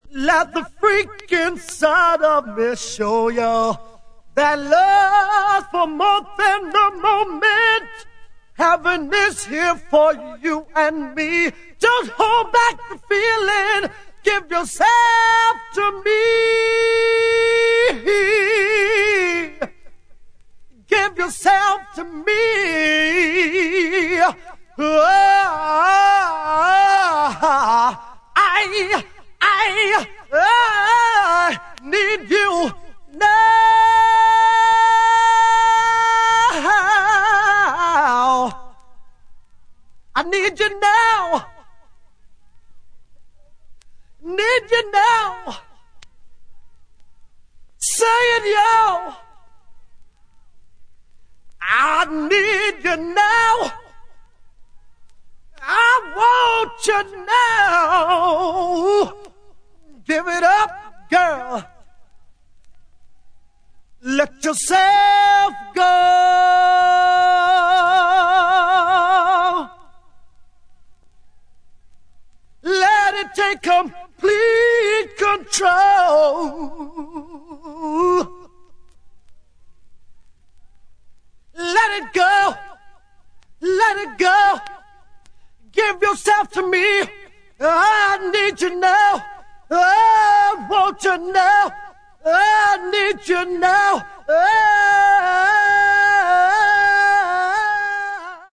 ジャンル(スタイル) DISCO / GARAGE / DANCE CLASSIC